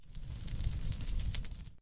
fire.ogg